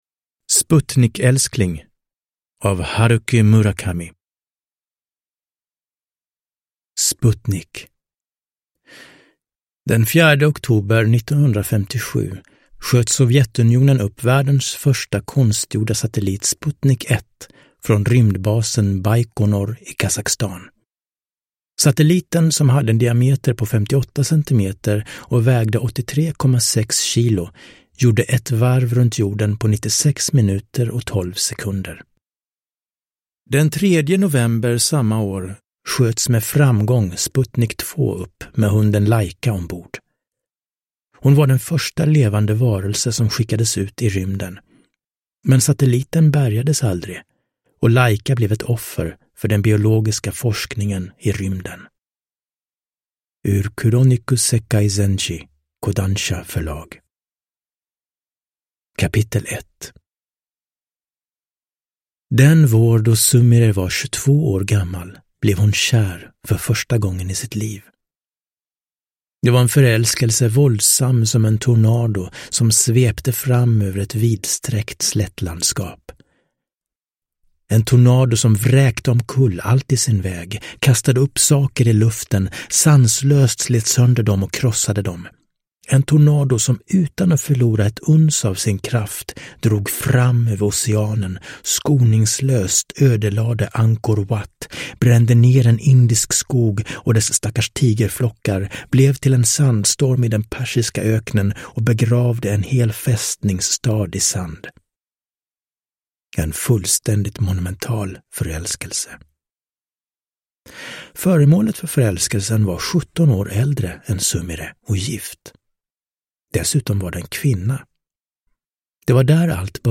Sputnikälskling – Ljudbok – Laddas ner